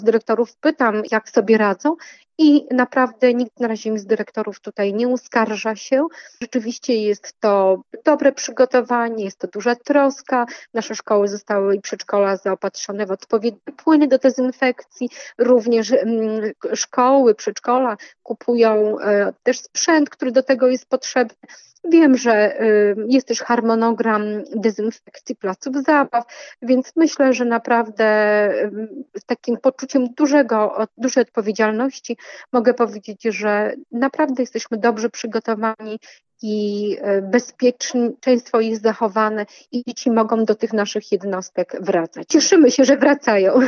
Jak mówi Ewa Sidorek, zastępca prezydenta miasta do spraw oświaty, rodzice są ostrożni.